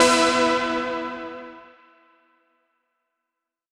Index of /musicradar/future-rave-samples/Poly Chord Hits/Straight
FR_SARP[hit]-E.wav